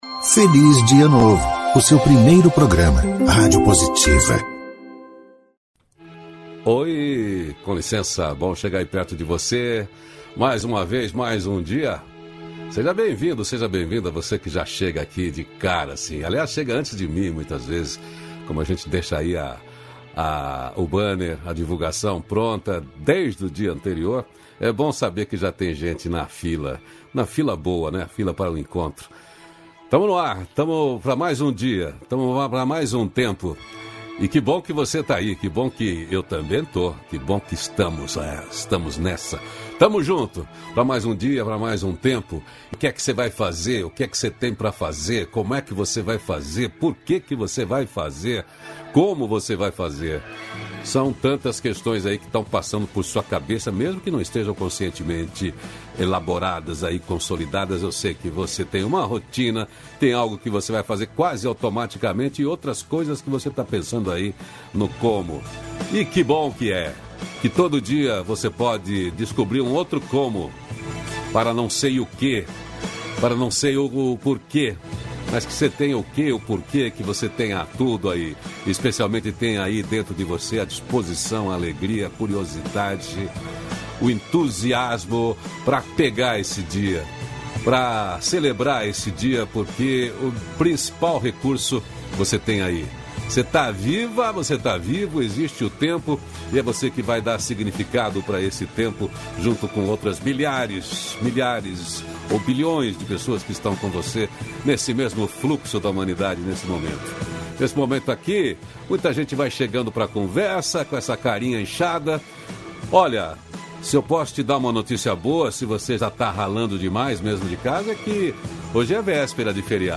-466FelizDiaNovo-Entrevista.mp3